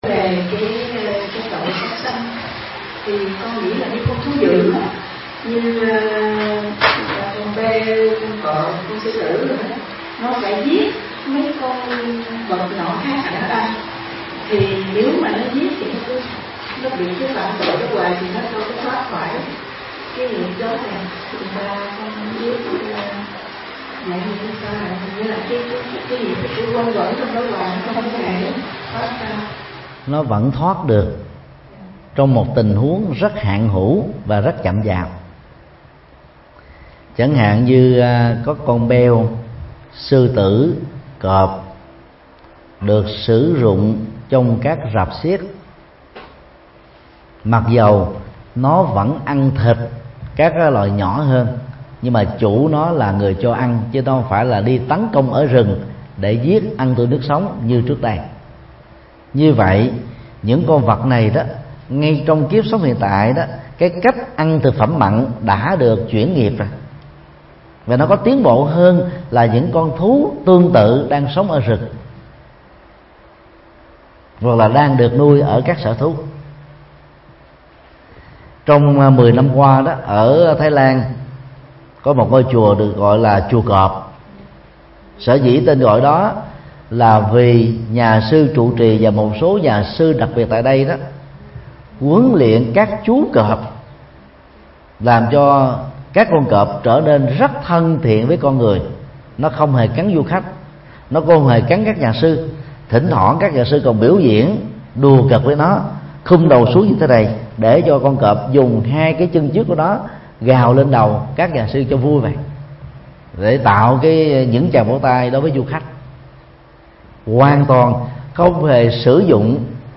Vấn đáp: Thoát kiếp động vật – Thích Nhật Từ